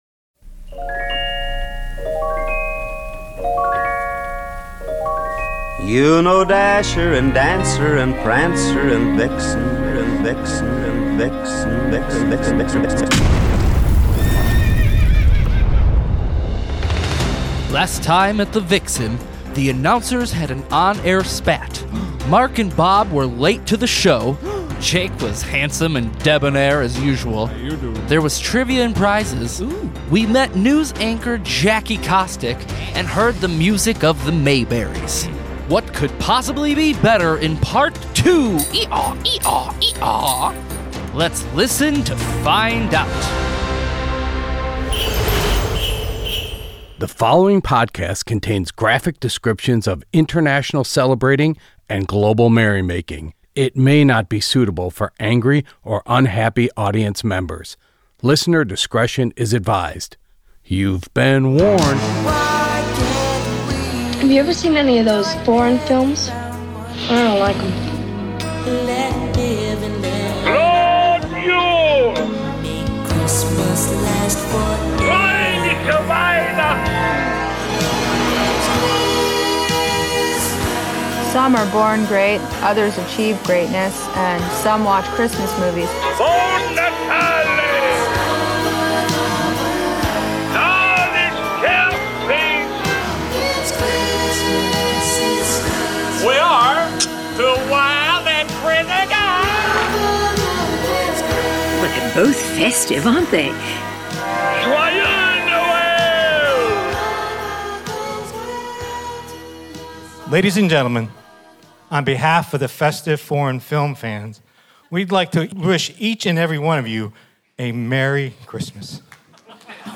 There was something electric about this year’s live show — the space, the stage, the lights, the crowd, the music — but what really transformed the night into something unforgettable was our special guests.
When you listen to the interviews, you realize we that were connecting with some remarkable human beings.